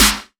Chart Snare 01.wav